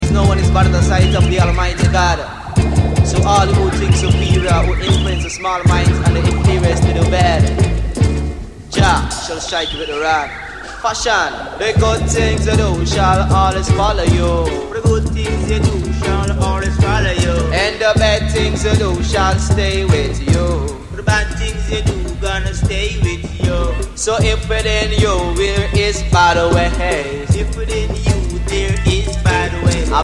Reggae Ska Dancehall Roots Vinyl Schallplatten ...